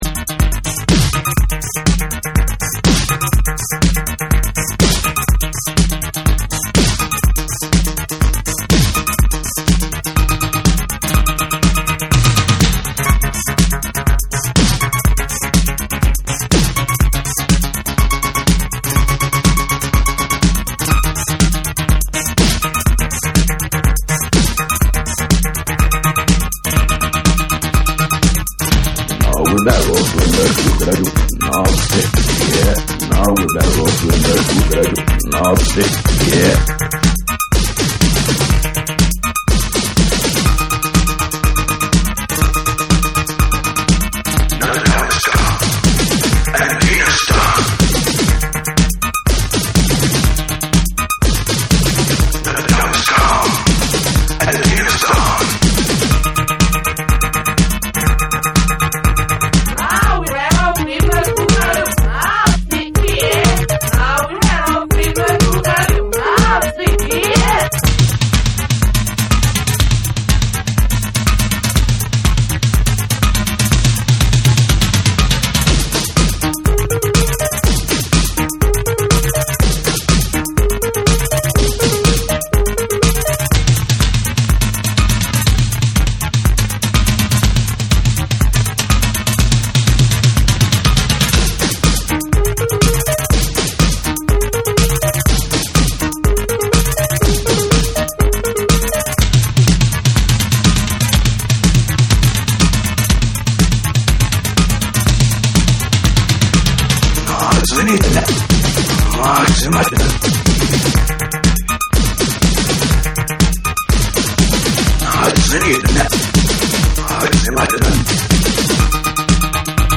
疾走する4/4トラックに煌めくシンセが高揚感を煽る2は
TECHNO & HOUSE / RE-EDIT / MASH UP